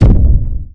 giant3.wav